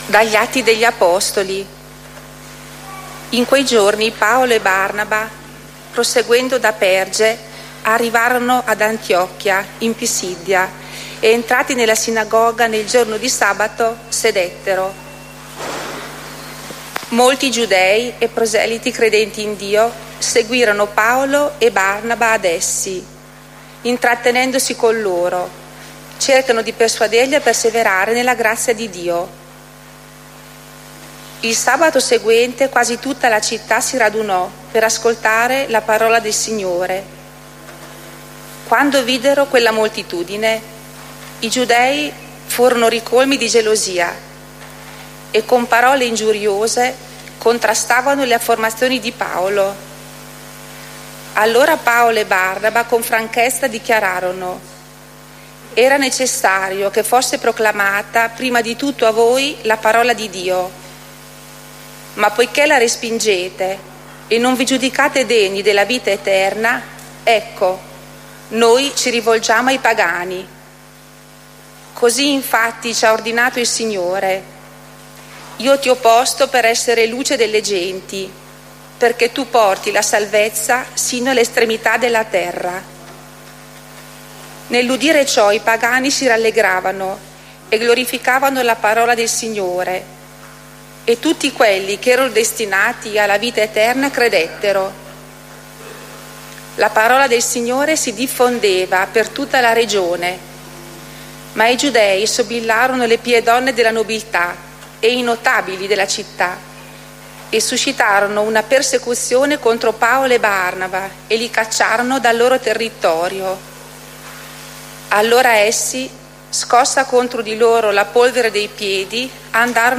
A questo link è possibile scaricare l'audio delle letture e dell'omelia della Messa domenicale delle ore 10,00.
LettureOmelia.mp3